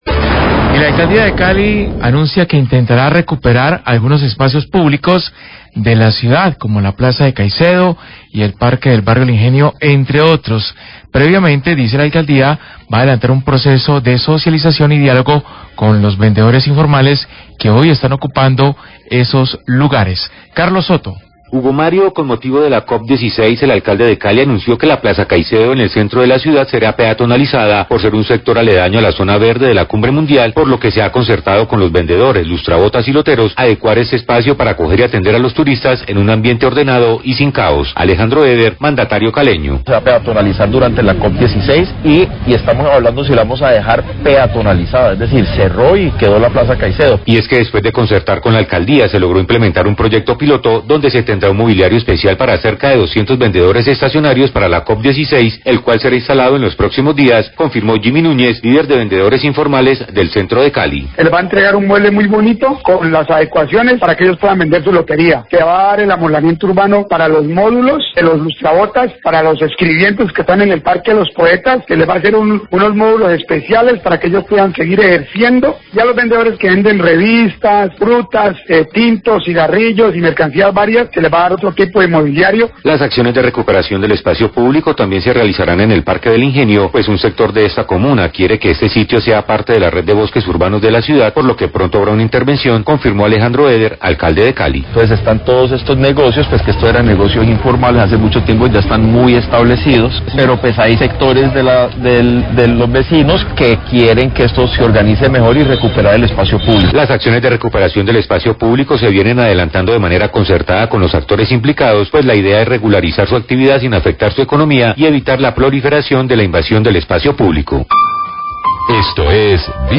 Alcalde Eder habla de la recuperación de espacios públicos ocupados por ventas ambulantes
Radio